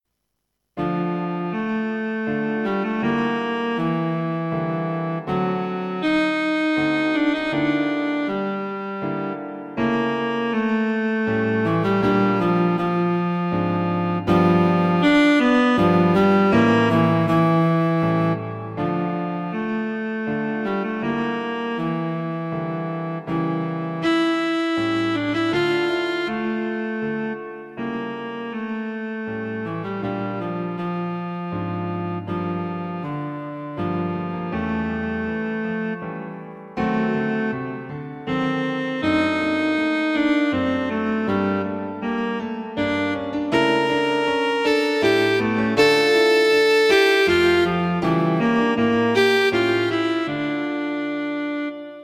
A gentle piece in A minor.